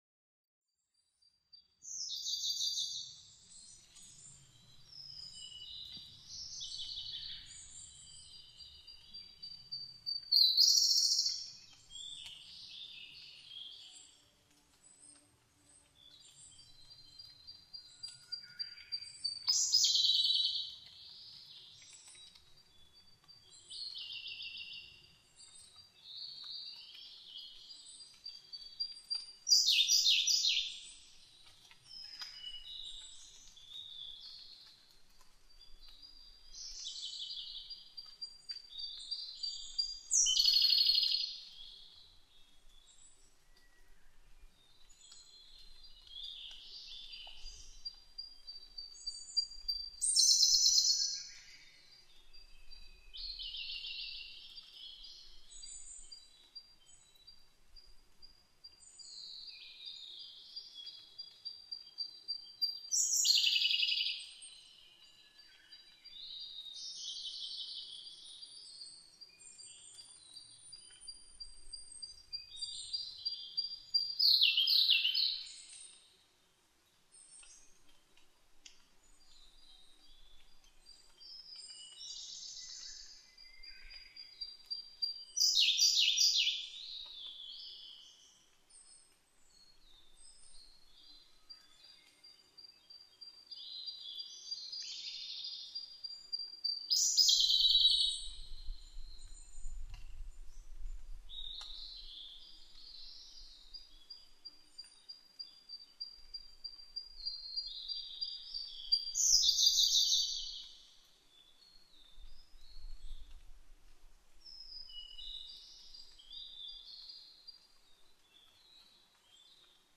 コルリ　Luscunia cyaneツグミ科
日光市土呂部　alt=1210m
Mic: built-in Mic.
他の自然音：ウグイス、オオルリ